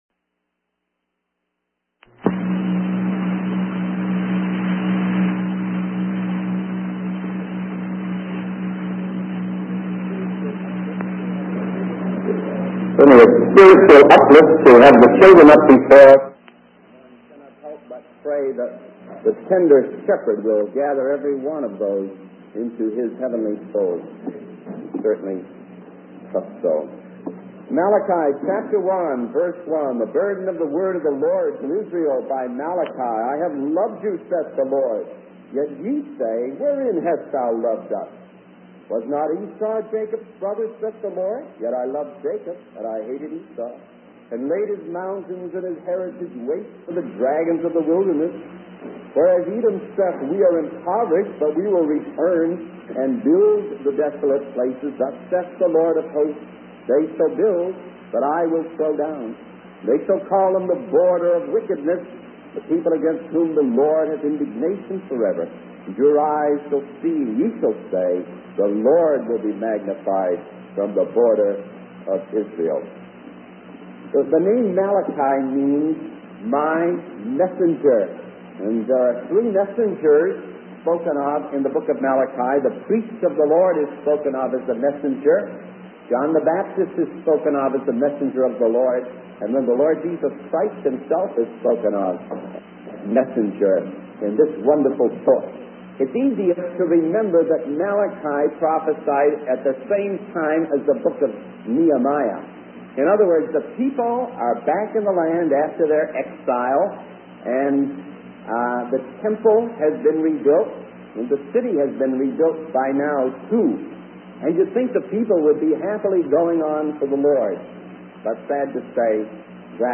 In this sermon, the preacher focuses on the book of Malachi in the Bible.